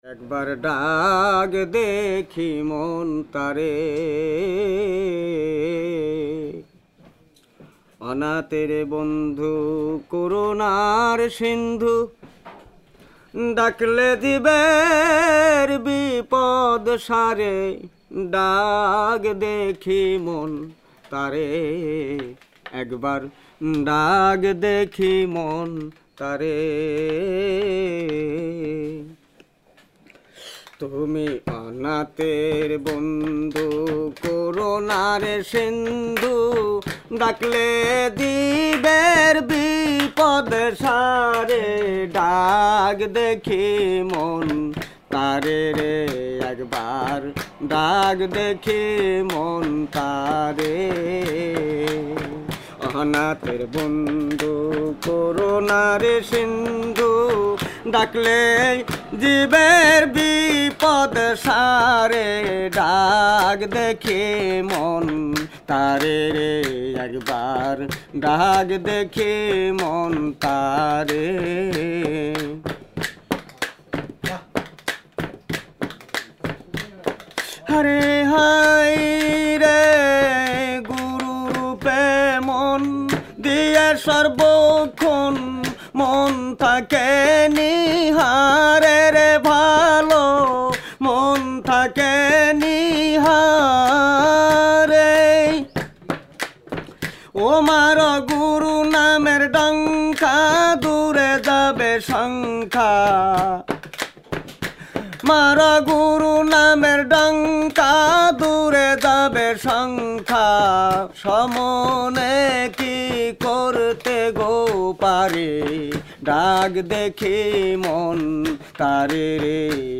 Baul
Such was the wish, to find quietness, but motorised boats and mobile phones make a hell of a lot of noise.
Char on the Padma
In the background there were other voices; we hear them in the recording.